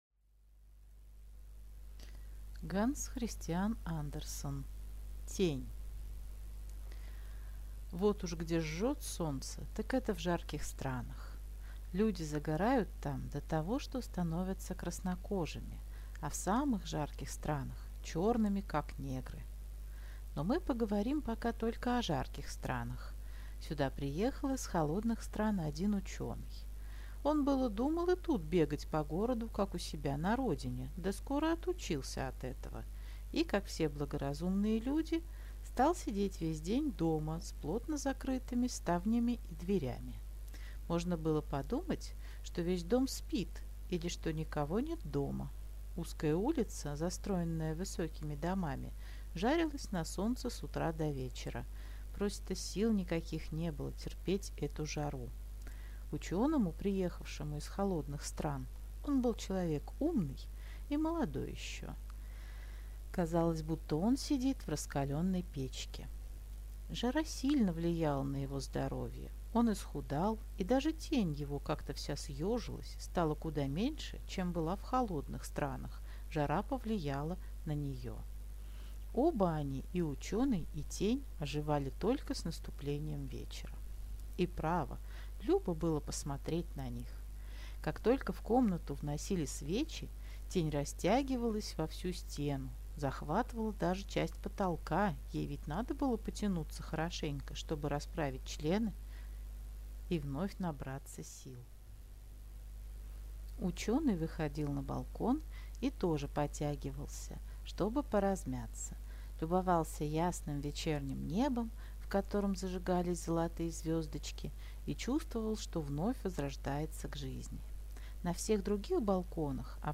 Аудиокнига Тень | Библиотека аудиокниг